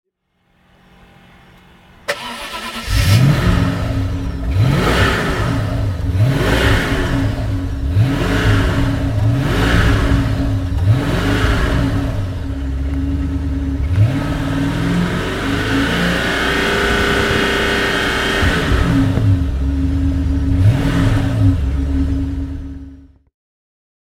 Maserati Quattroporte 3.2i 8V Evolutione (1998) - Starten und Leerlauf
Maserati_Quattroporte_1998.mp3